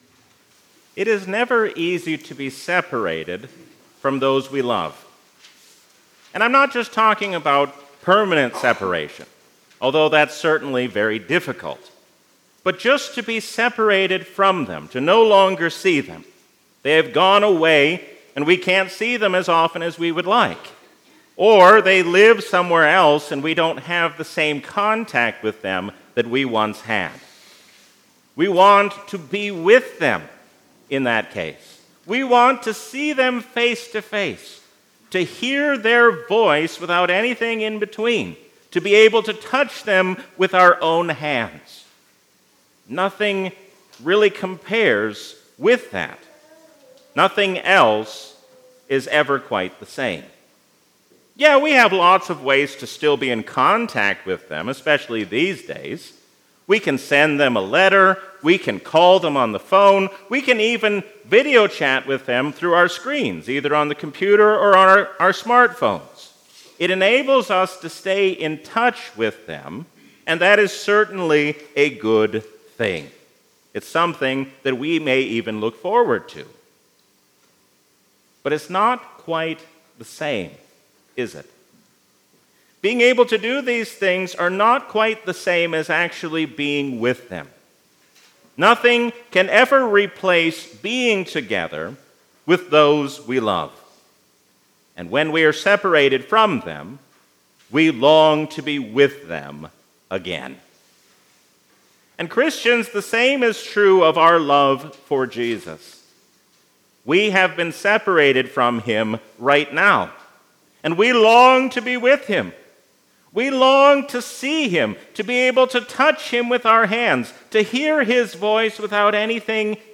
A sermon from the season "Easter 2023." Knowing who the Holy Spirit is changes how we live as Christians.